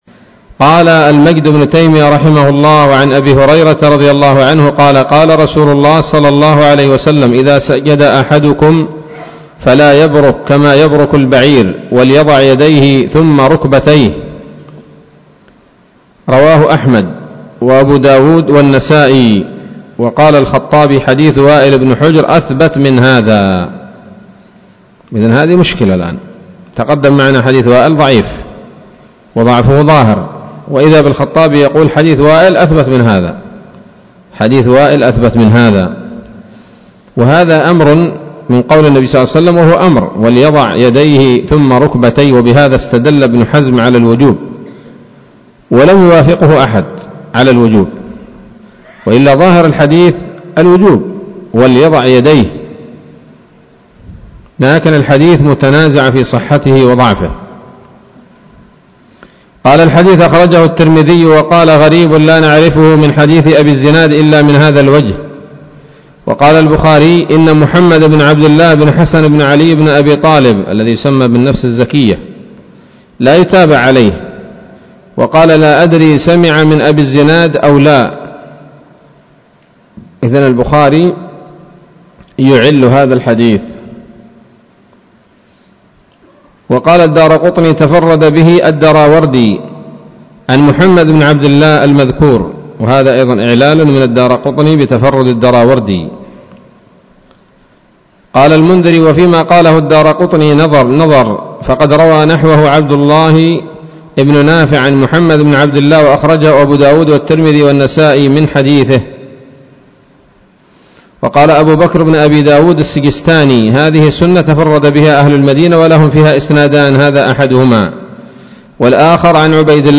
الدرس الحادي والستون من أبواب صفة الصلاة من نيل الأوطار